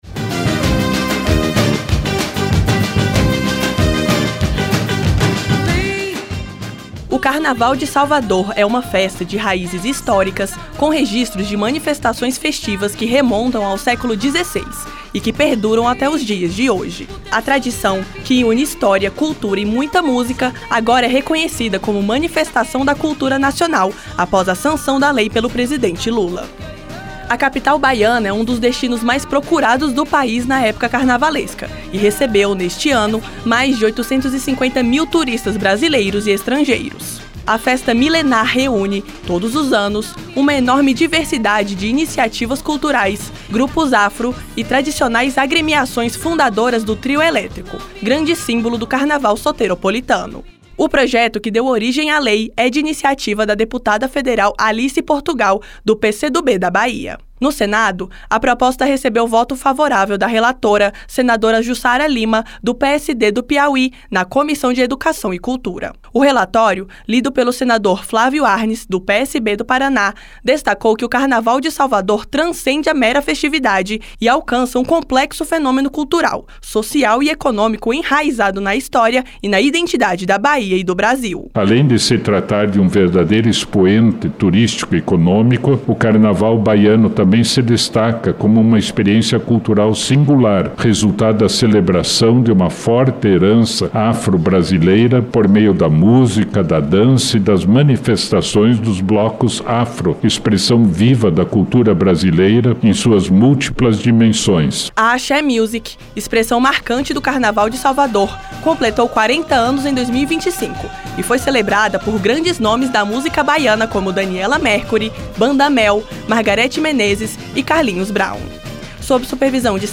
2. Notícias